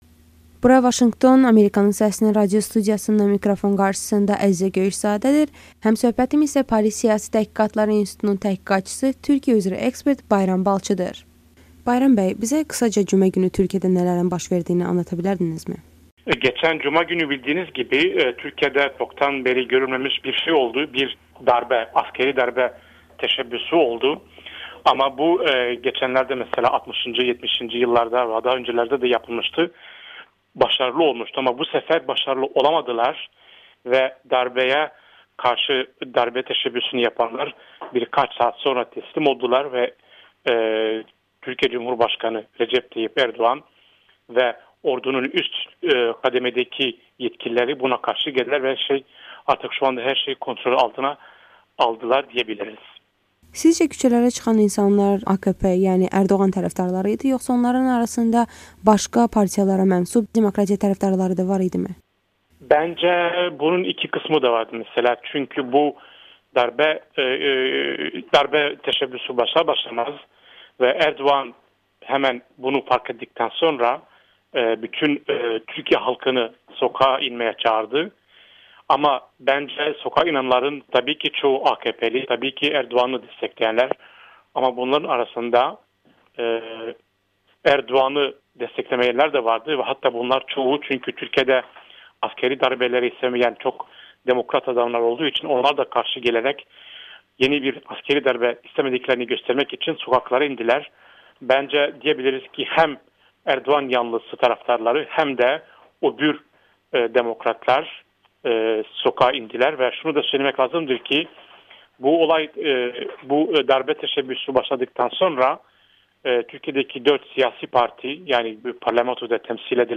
Amerikanın Səsinə müsahibəsi